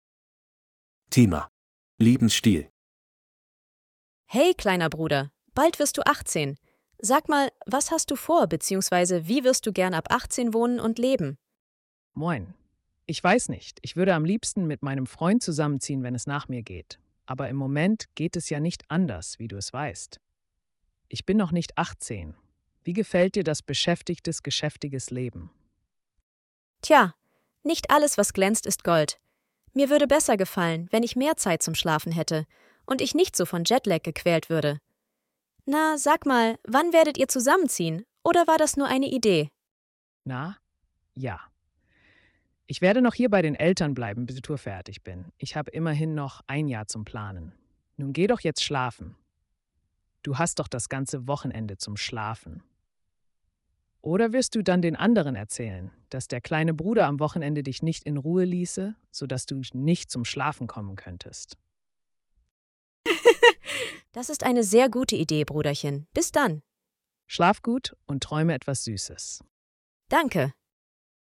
Hörtext für die Dialoge bei Aufgabe 4: